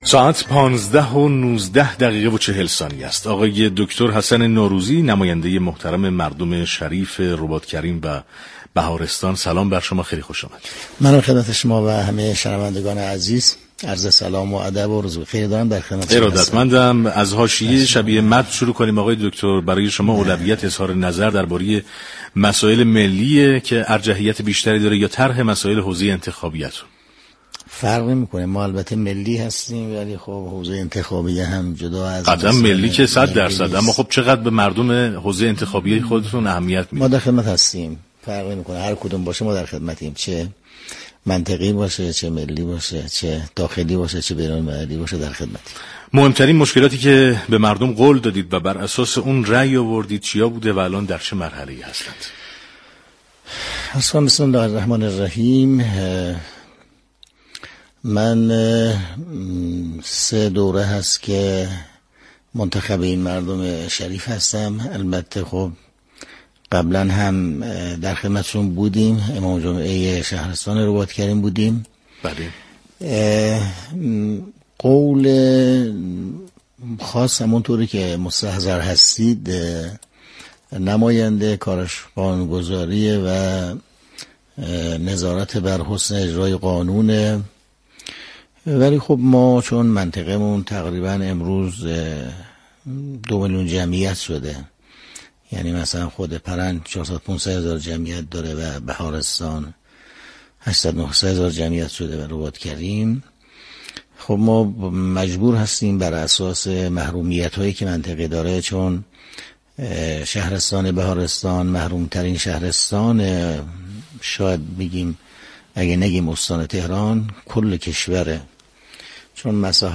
به گزارش پایگاه اطلاع رسانی رادیو تهران، حجت الاسلام حسن نورزوی نماینده مردم رباط كریم و بهارستان با حضور در استودیو پخش زنده برنامه "پل مدیریت" رادیو تهران با اشاره به اینكه پیش از نمایندگی مجلس، امام جمعه شهرستان رباط كریم بوده است گفت: مسئولیت نمایندگان مجلس قانونگذاری و نظارت بر حسن اجرای قانون است.